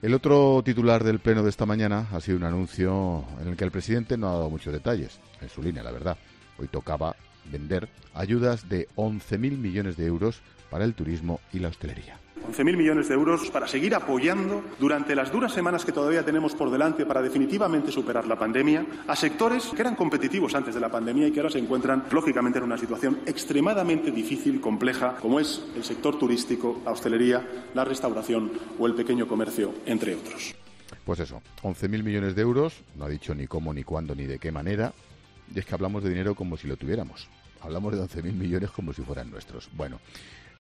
El presentador de 'La Linterna' pone en entredicho las palabras del presidente del Gobierno en el Congreso sobre las ayudas